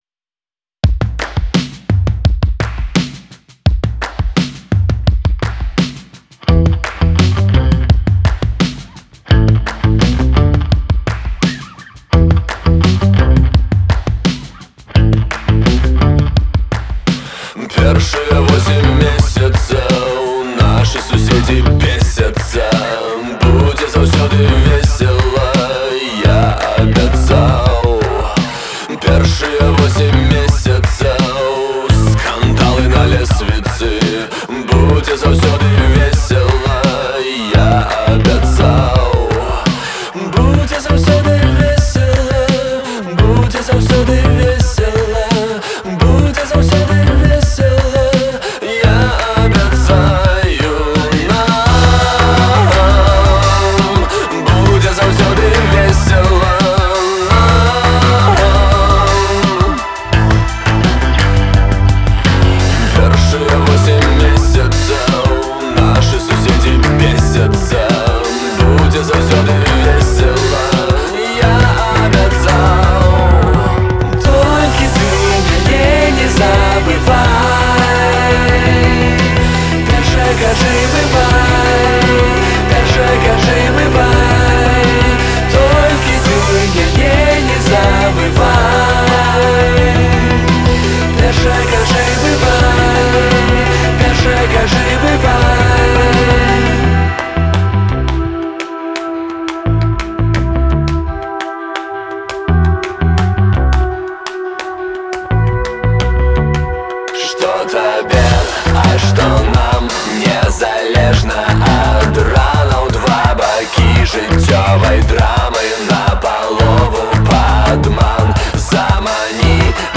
вакал, гітара
бас-гітара